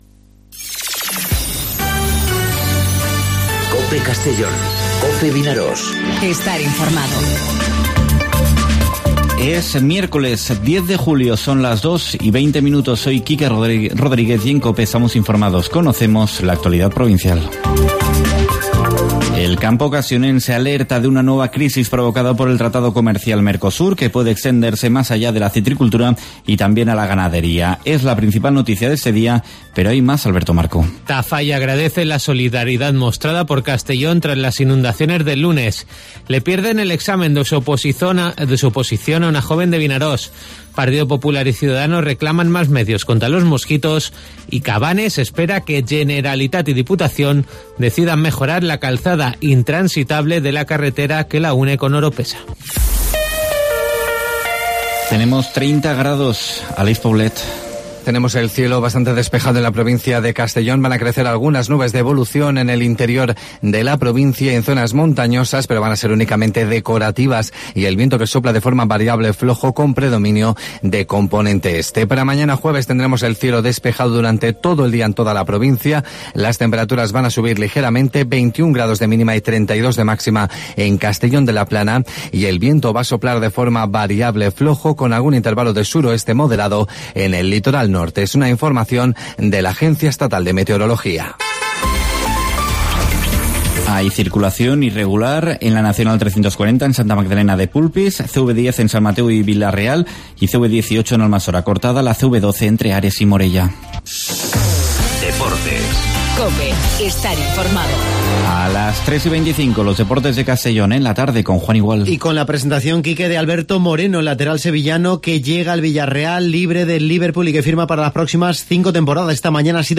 Informativo 'Mediodía COPE' en Castellón (10/07/2019)